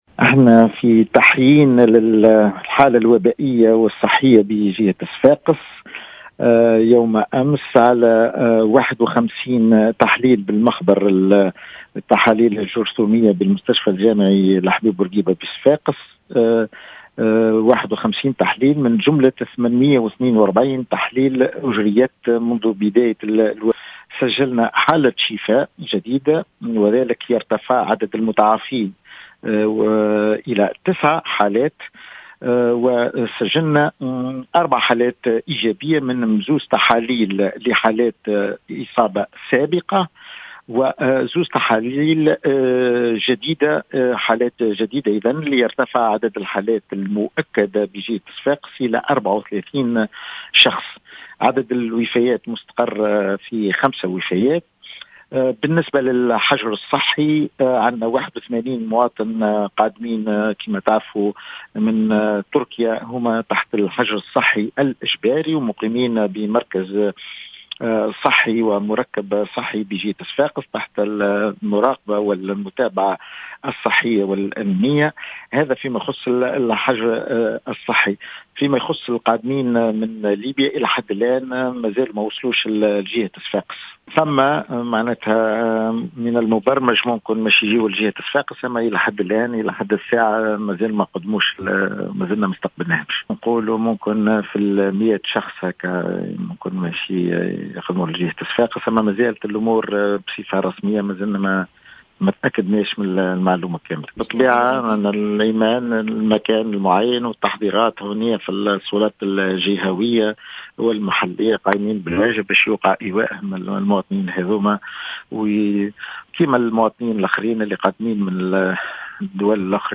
و أشار العيّادي في تصريح للجوهرة أف أم، إلى تسجيل حالة شفاء جديدة و بالتالي يرتفع عدد المتماثلين للشفاء إلى 9 أشخاص.